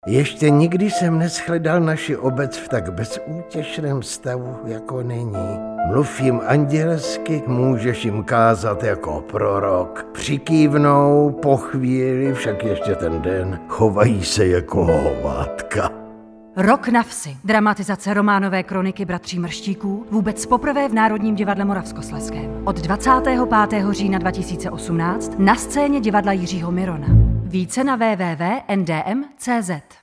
Oficiální audiospot – NDM